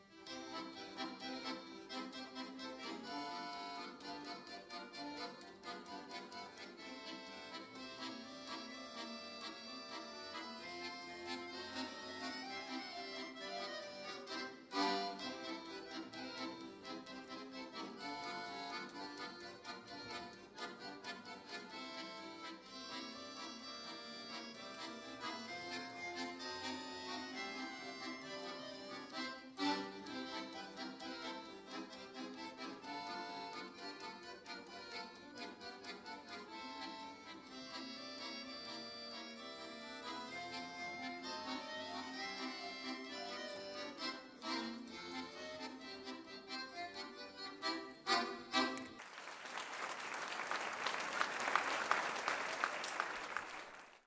Tout au long du mois de mai, l’école intercommunale de musique de Petite Camargue a présenté 6 concerts des élèves à Vauvert, Beauvoisin, Aimargues, Aubord et le Cailar.
07_accordeon
Musique Nino Rota par la classe d’Accordéon